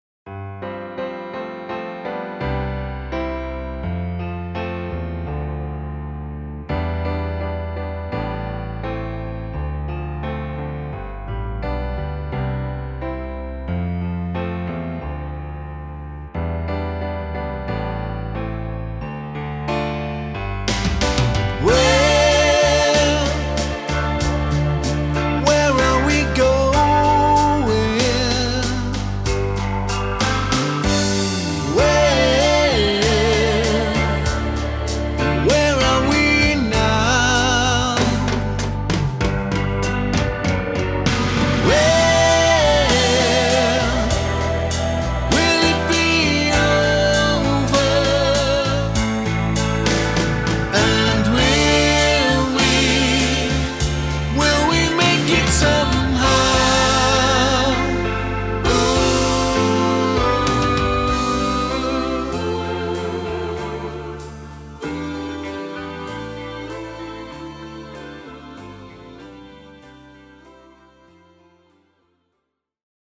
CLASSIC SONG COVERS
Backing vocals…